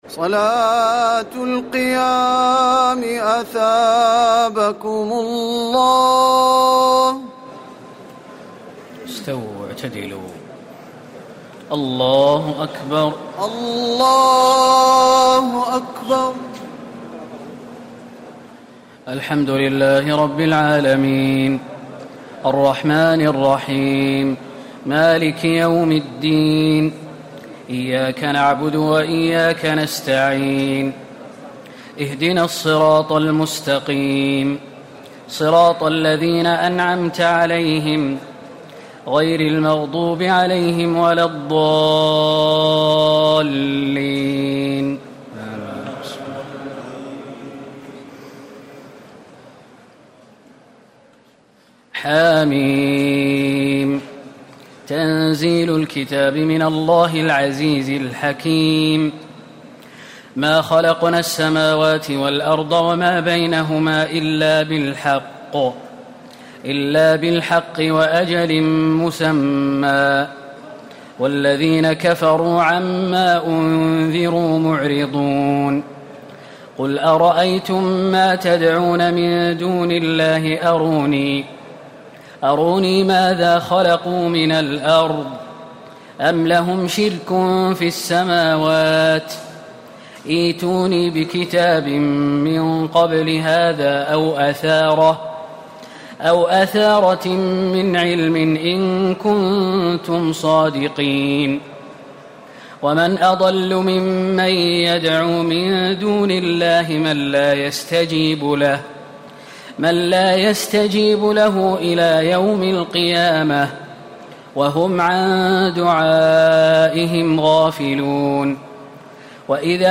تراويح ليلة 25 رمضان 1439هـ من سور الأحقاف و محمد والفتح 1-17 Taraweeh 25 st night Ramadan 1439H from Surah Al-Ahqaf and Muhammad and Al-Fath > تراويح الحرم النبوي عام 1439 🕌 > التراويح - تلاوات الحرمين